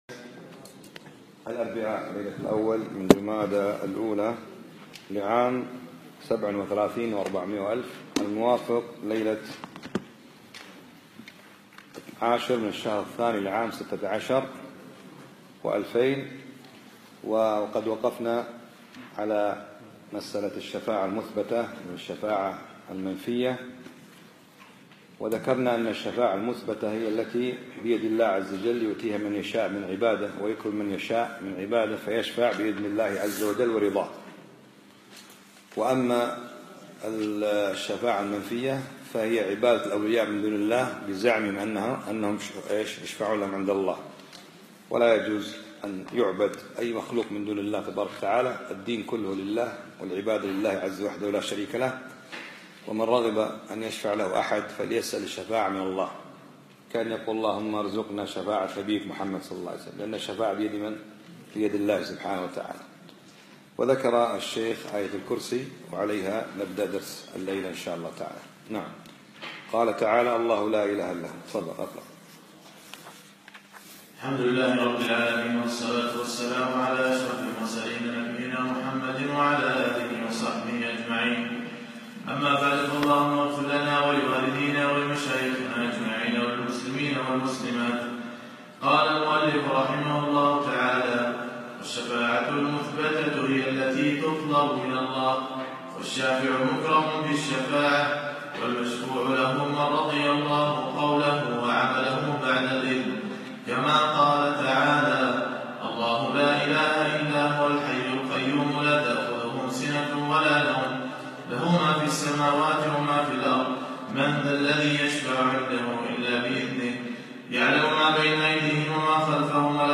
يوم الثلاثاء 30 ربيع الأخر 1437هـ الموافق 9 2 2016م في مسجد سعد السلطان الفنطاس